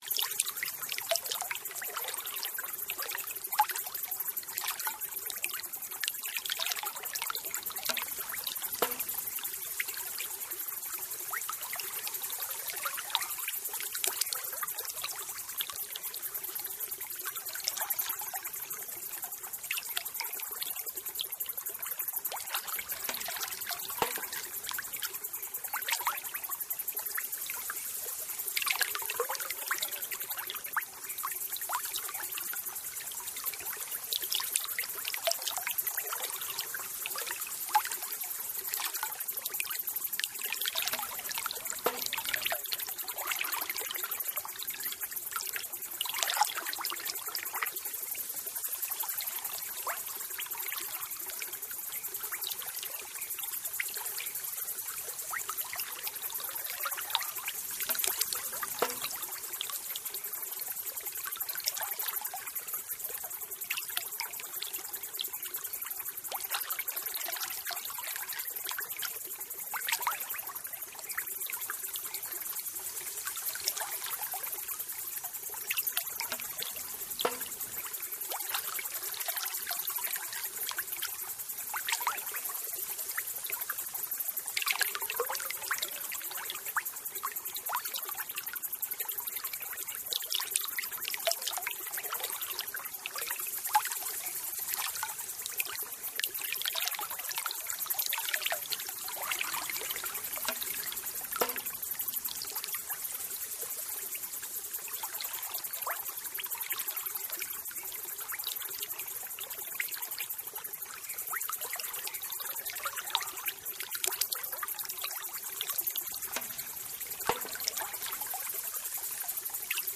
Garden.mp3